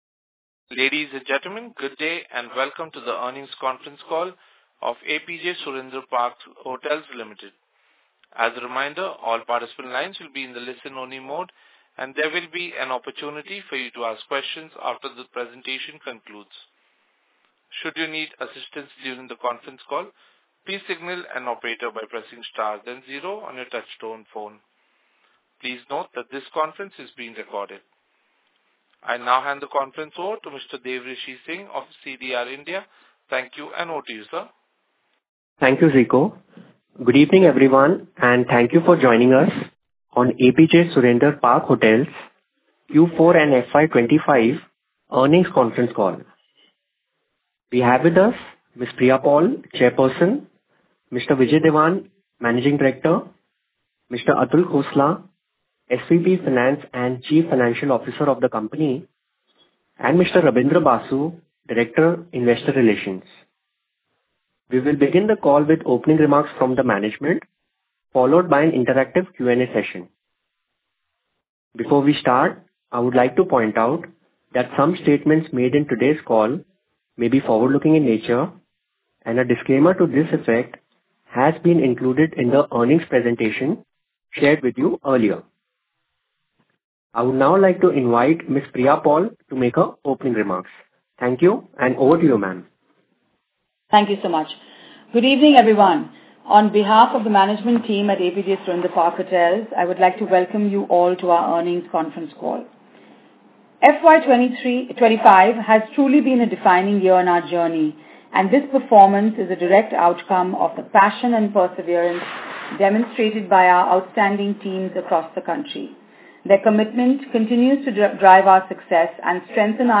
Concalls
q4-earnings-call.mp3